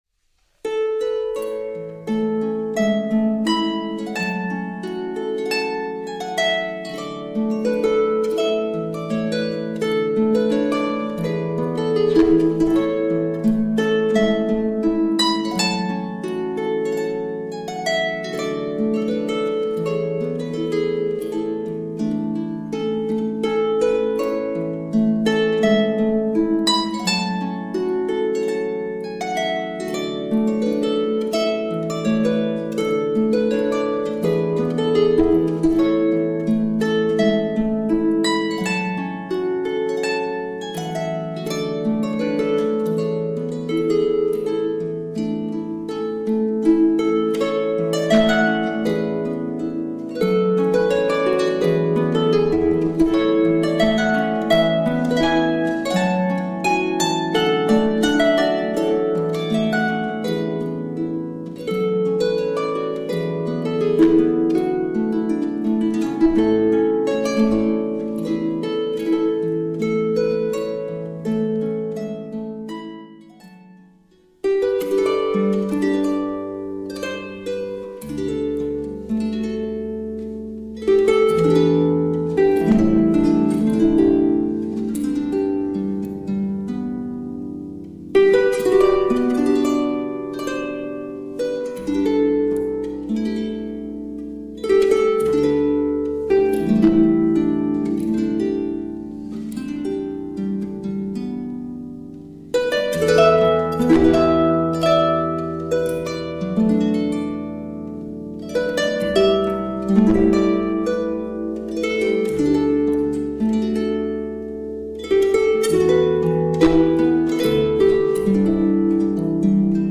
traditional Scottish music on Celtic harp
Sound sample of some Scottish pieces